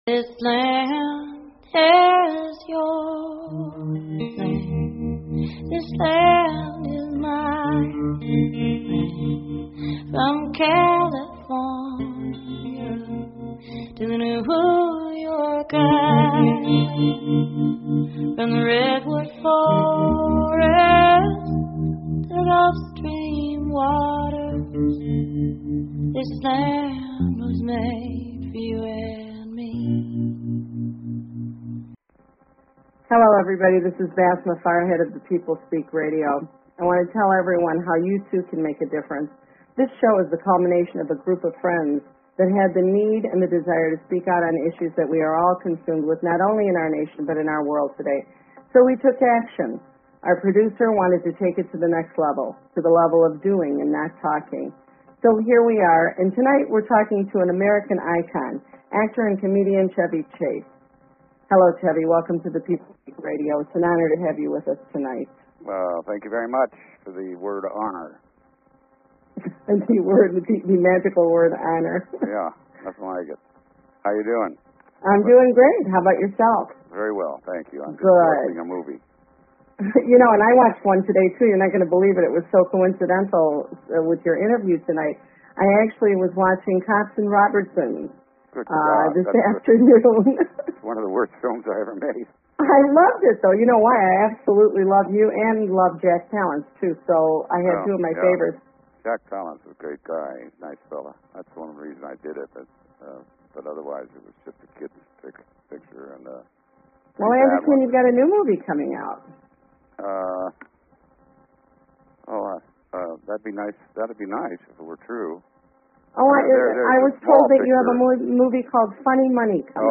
Talk Show Episode, Audio Podcast, The_People_Speak and Chevy Chase on , show guests , about , categorized as Arts,Entertainment,Politics & Government
Guest, Chevy Chase